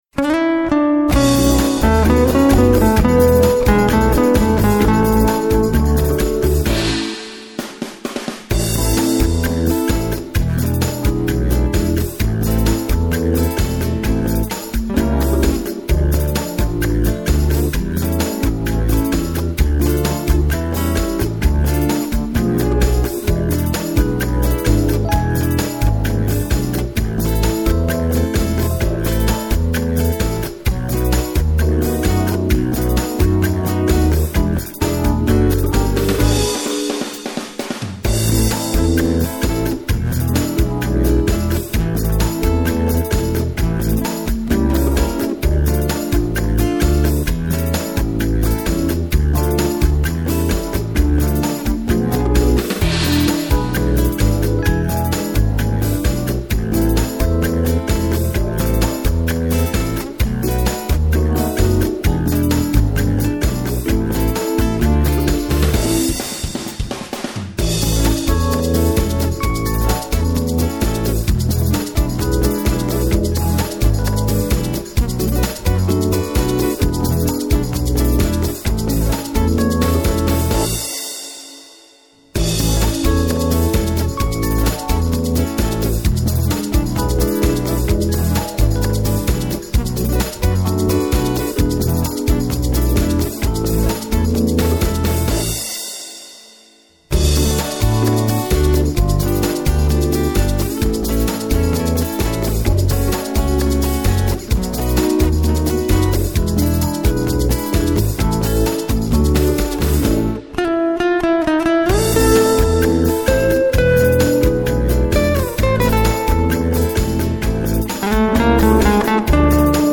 LE PLAY BACK
rythmique